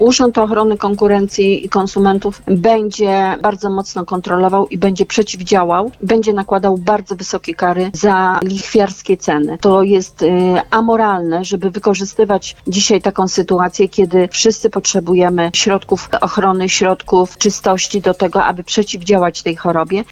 Pracownicy Urzędu Ochrony Konkurencji i Konsumentów oraz Inspekcji Handlowej będą kontrolować ceny żywności i produktów higienicznych. Informację przekazała na naszej antenie Anna Kwiecień, poseł Prawa i Sprawiedliwości.